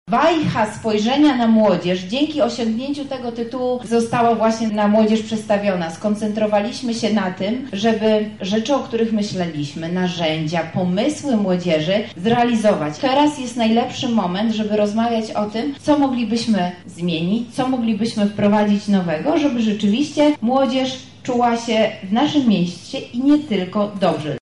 • mówi Beata Stepaniuk-Kuśmierzak, Zastępczyni Prezydenta ds. Kultury, Sportu i Partycypacji.